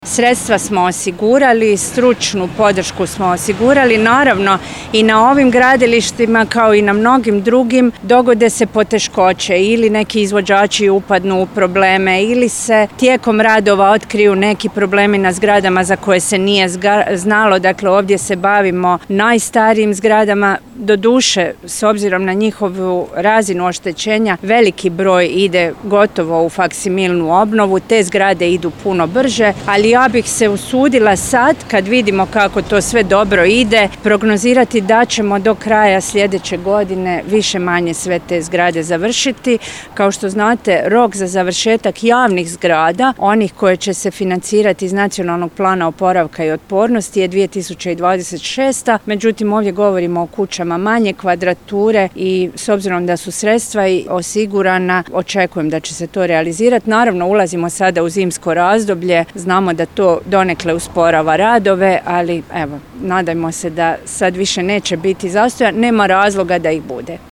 „Vjerujem da bi tijekom sljedeće godine sami centar Petrinje trebao biti završen”, zaključuje ministrica